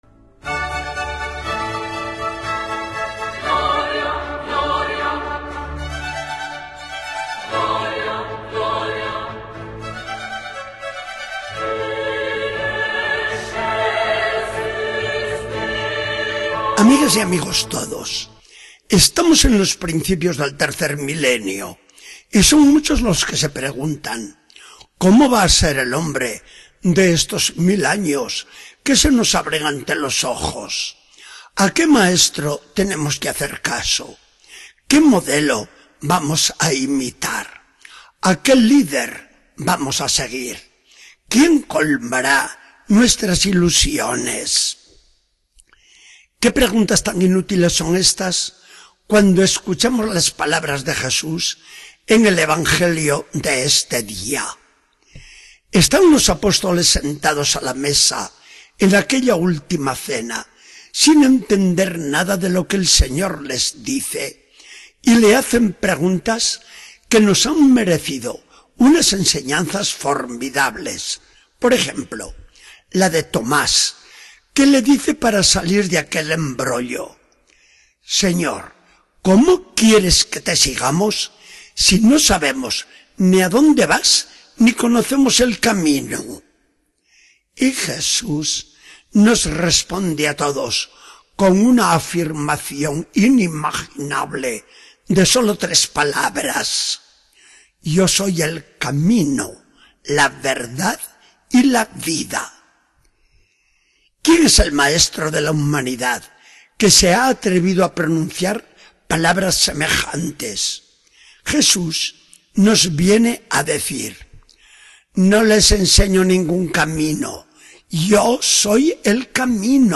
Charla del día 18 de mayo de 2014. Del Evangelio según San Juan 14, 1-12.